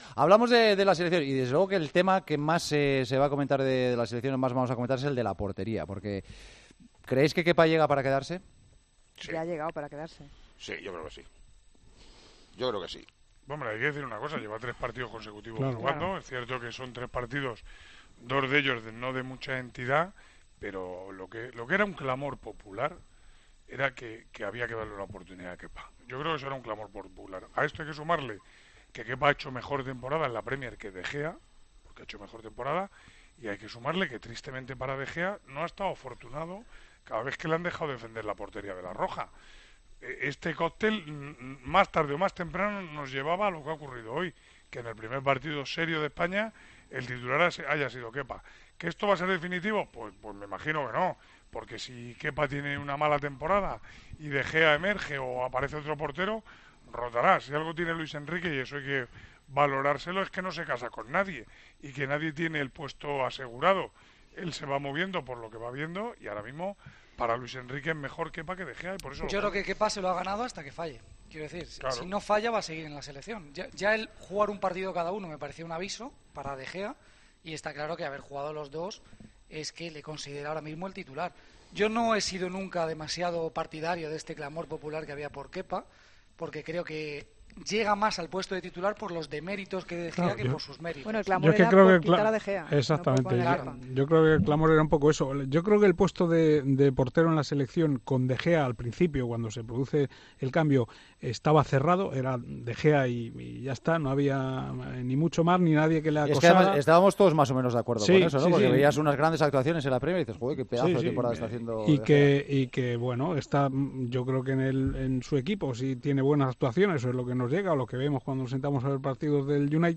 Los comentaristas de El Partidazo de COPE analizan la rotación en la portería de la Selección española.